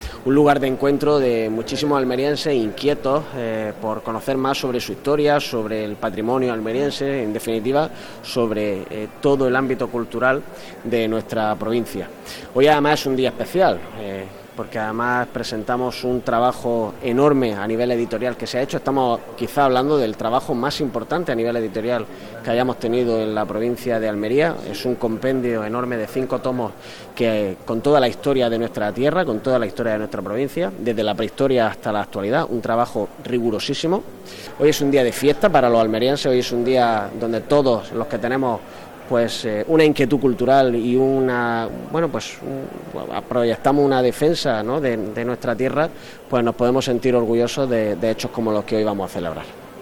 El Patio de Luces de la institución provincial ha acogido esta nueva cita del Instituto de Estudios Almerienses que ha tratado sobre el proyecto editorial de ‘Historia de Almería’
07-03_tardes_iea_diputado.mp3